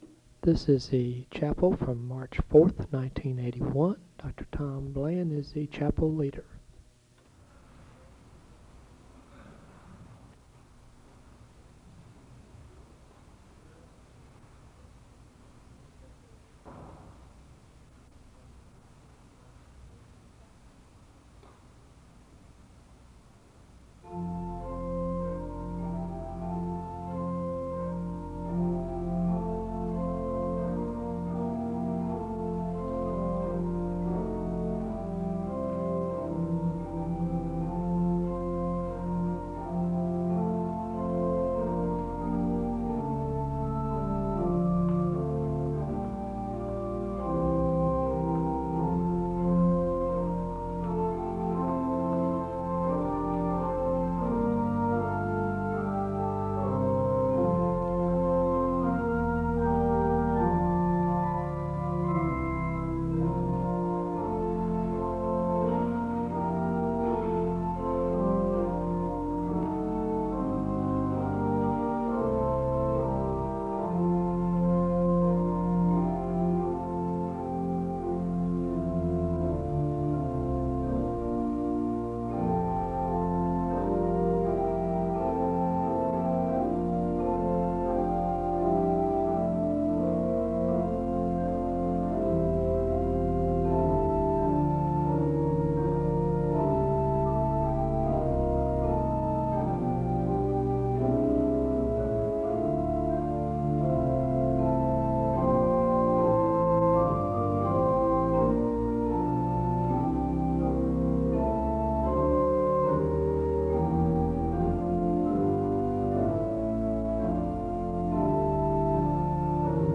The service opens with the song “My Jesus, I Love Thee” being played on the organ (00:00-04:29).
The speaker reads from Psalm 51, and he gives a word of prayer (04:30-10:07).
SEBTS Chapel and Special Event Recordings SEBTS Chapel and Special Event Recordings